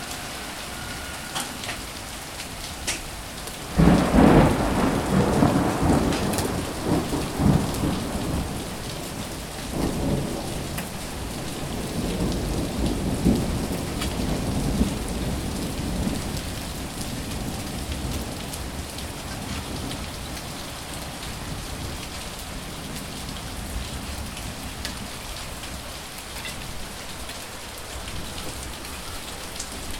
thunder in Greece - violent storm 1.ogg
Recorded a violent wheather change storm in southern Greece, Kassandra region, Nea Skioni harbor with heavy raining and violent thunders.
Channels Stereo
thunder_in_greece_-_violent_storm_1_rd3.mp3